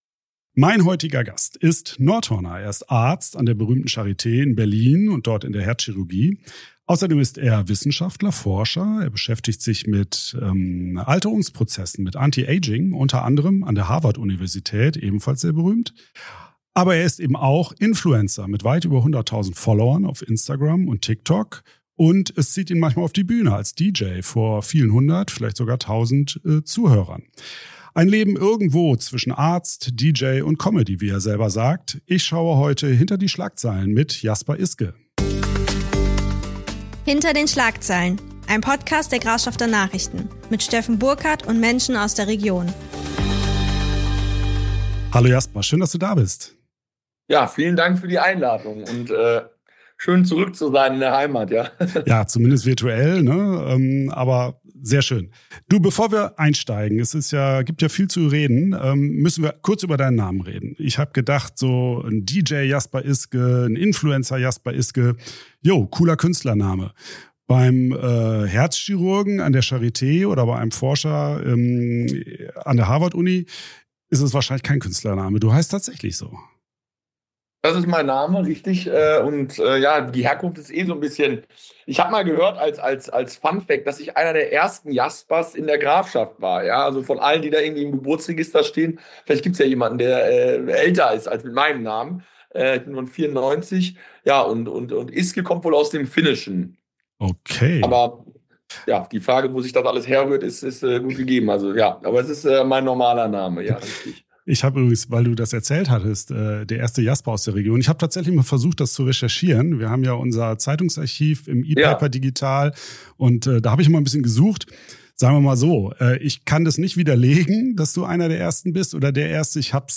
Ein Gespräch über Energie, Wissenschaft, Verantwortung, Rampenlicht und die Frage, wie all das zusammenpasst.